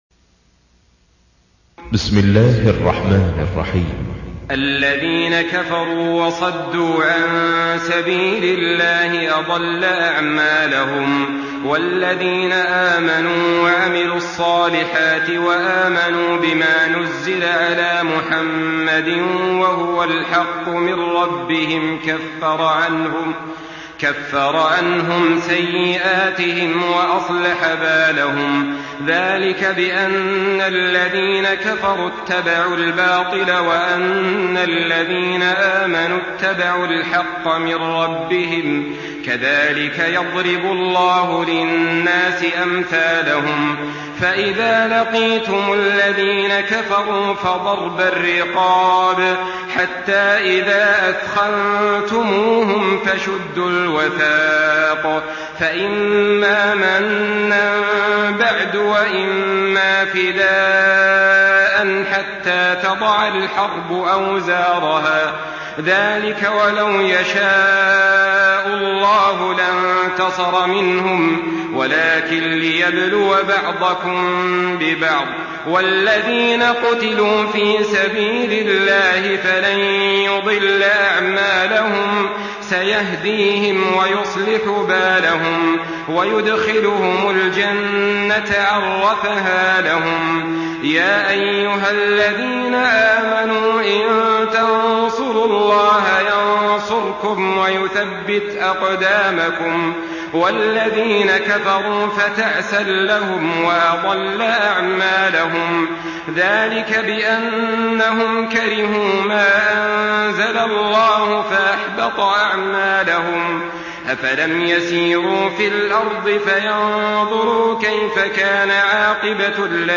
Surah মুহাম্মাদ MP3 by Saleh Al-Talib in Hafs An Asim narration.
Murattal Hafs An Asim